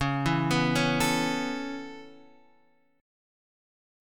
Dbm7#5 chord